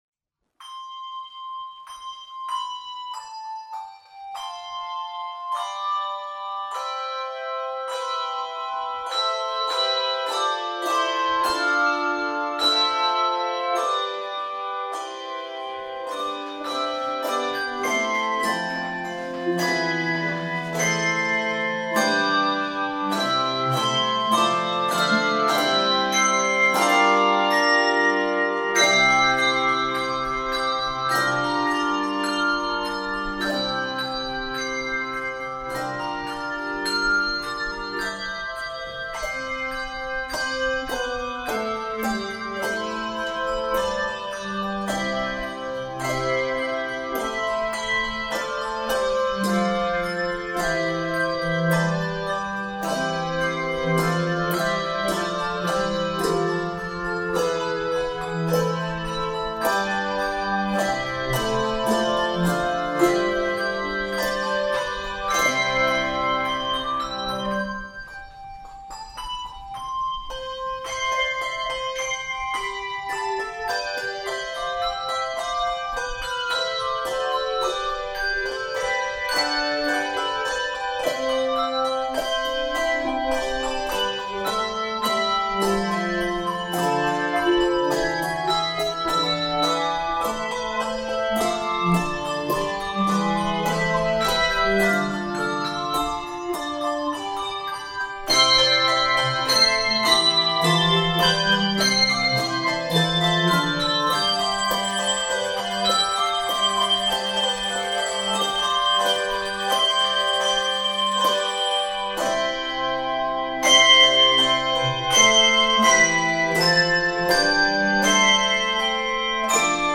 Voicing: Handbells 2-5 Octave